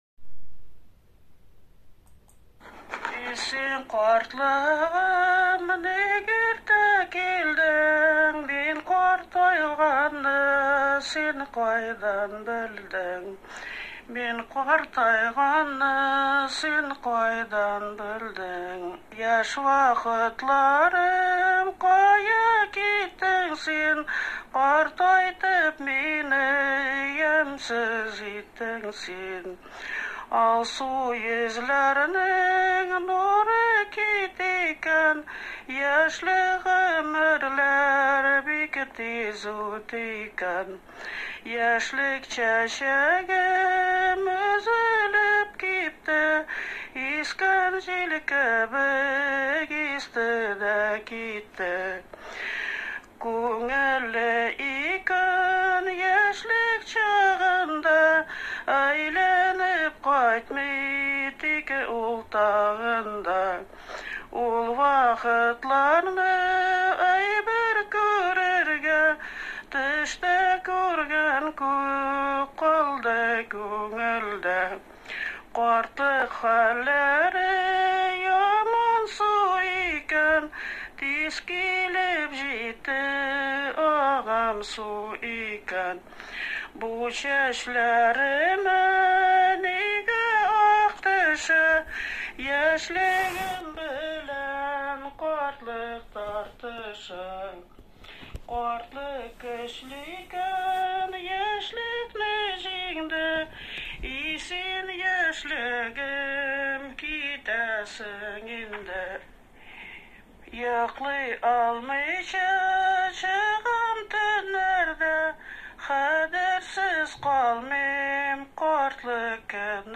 ПЕСЕННАЯ ТРАДИЦИЯ ОБСКИХ ЧАТОВ ИЗ НОВОСИБИРСКОЙ ОБЛАСТИ ВОШЛА В ПРОЕКТ «АНТОЛОГИЯ НАРОДНОЙ КУЛЬТУРЫ»